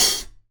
Open Hats
OpenHH Koopa 1.wav